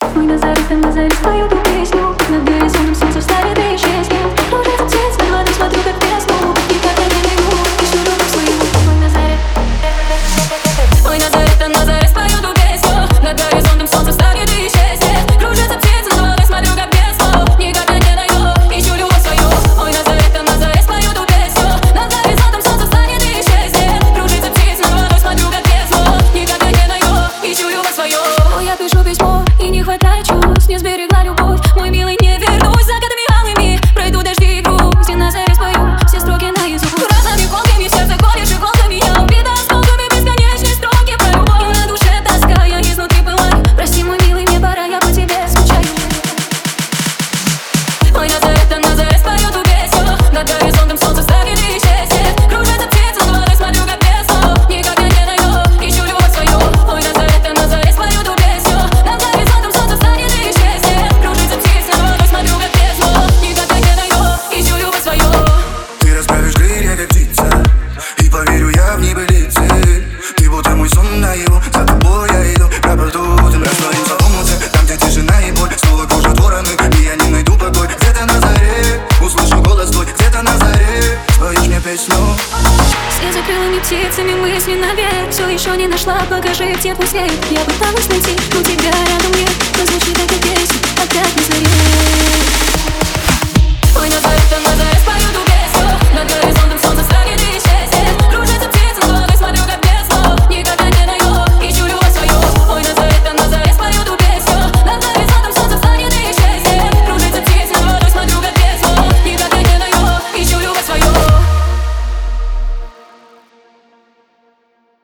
Новые танцевальные хиты
Танцевальная музыка
клубные ремиксы
Dance музыка